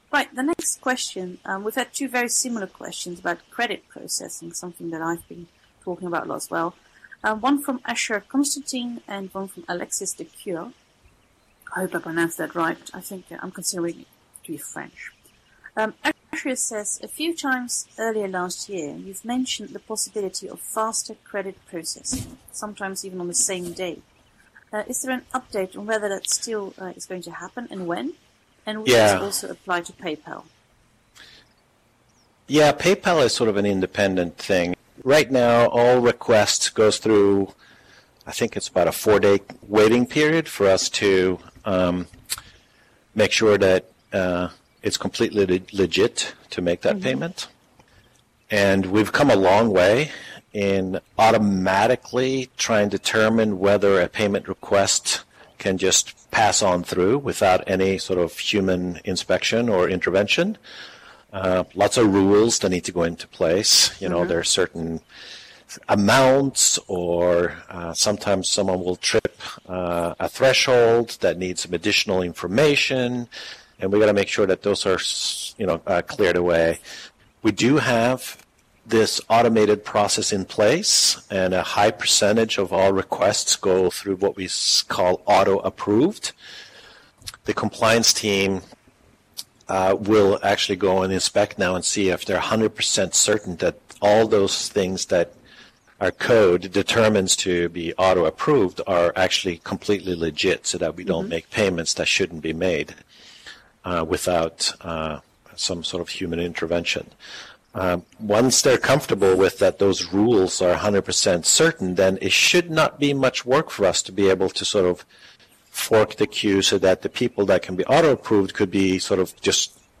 Each question and response is supplied with an accompanying audio extract.